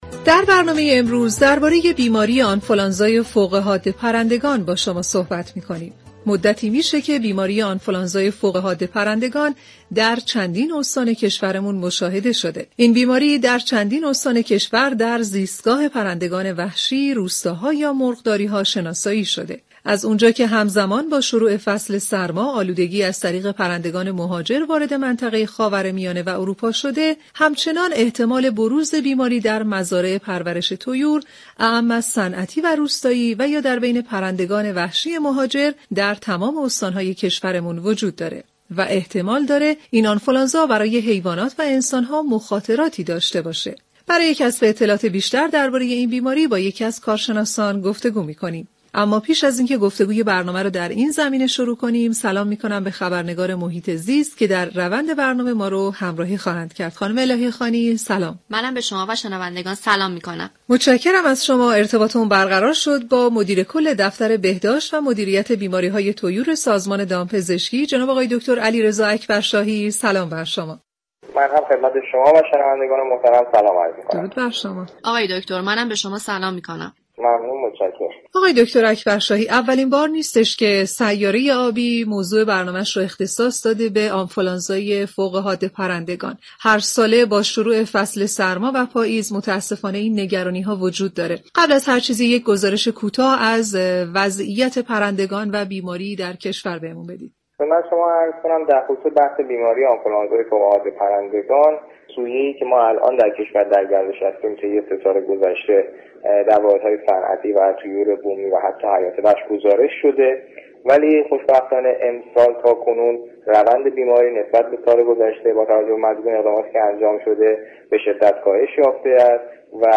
«علیرضا اكبرشاهی» مدیر كل دفتر بهداشت و مدیریت بیماری های طیور سازمان دامپزشكی در سیاره آبی رادیو ایران گفت